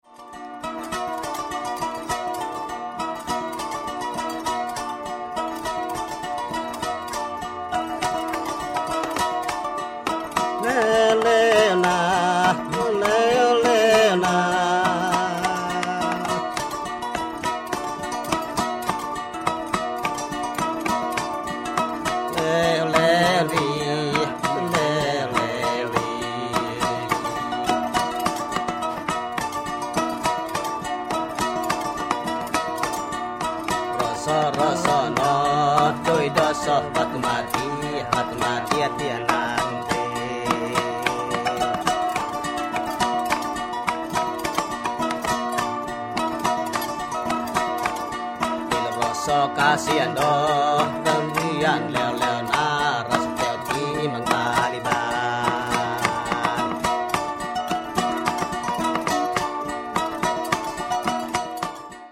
' and this song